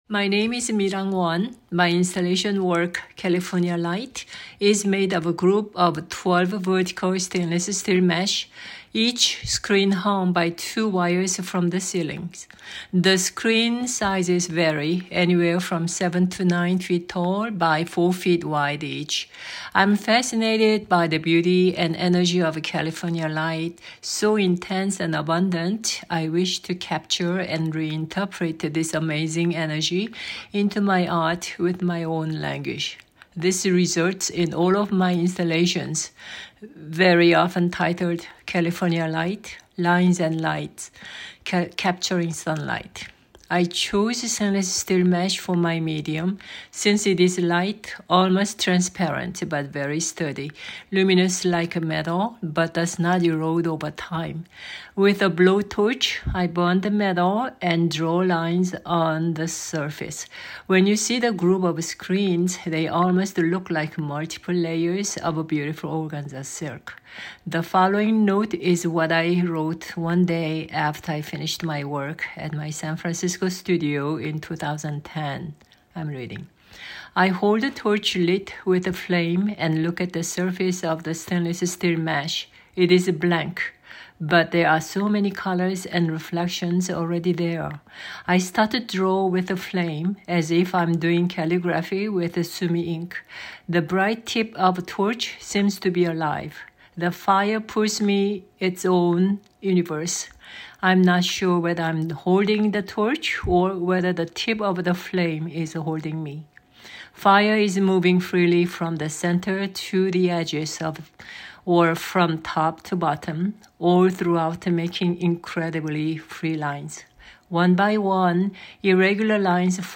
Audio Description for California Light: